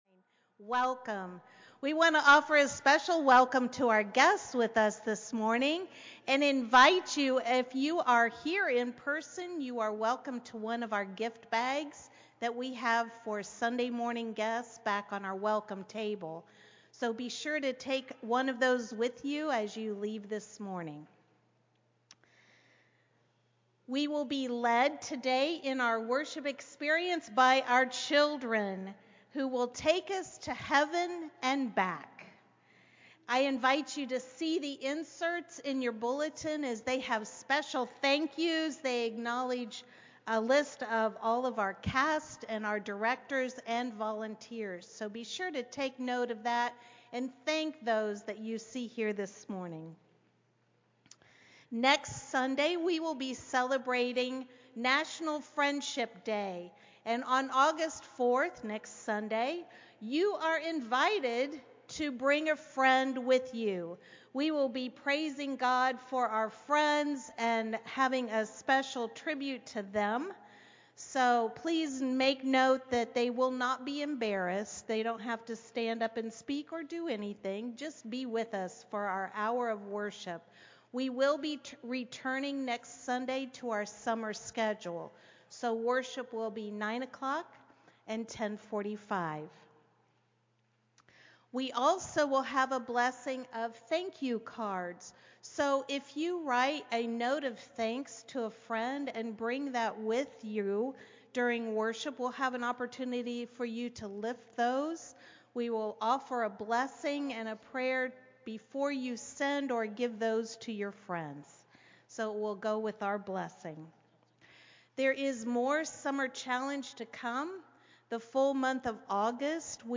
July 28, 2024-Faith News Children’s Musical